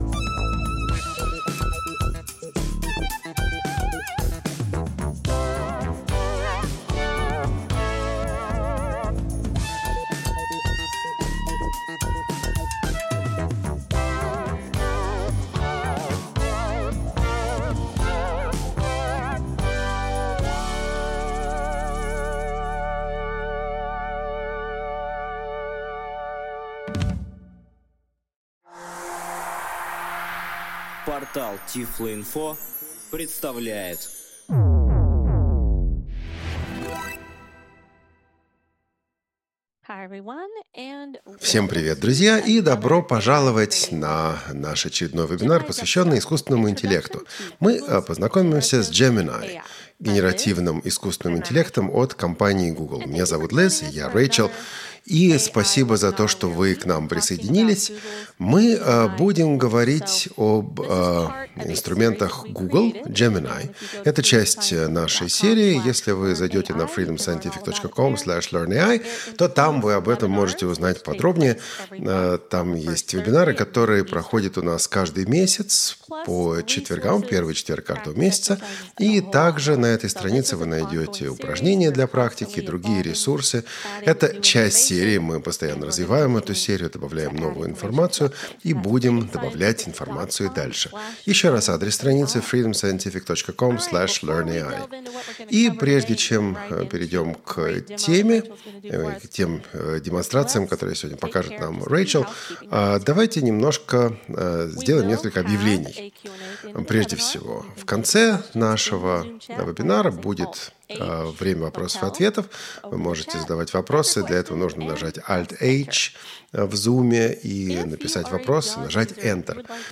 Вебинар.